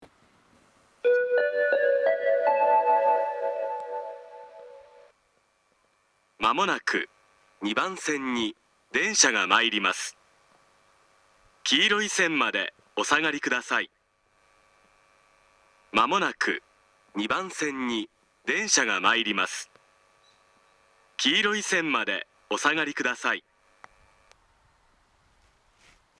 スピーカーが上下兼用なので、交換のある列車の場合、放送が被りやすいです。
仙石型（男性）
接近放送
ユニペックス小型（1・2番線）